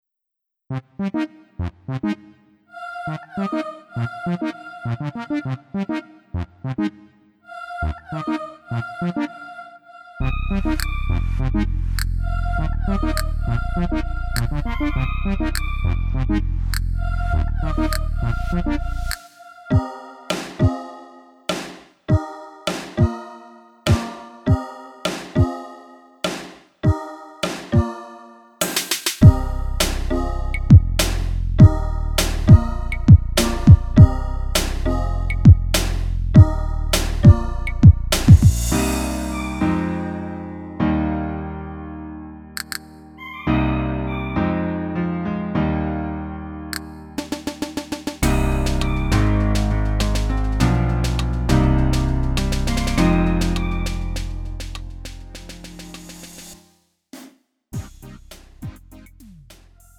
음정 -1키 3:25
장르 가요 구분 Lite MR
Lite MR은 저렴한 가격에 간단한 연습이나 취미용으로 활용할 수 있는 가벼운 반주입니다.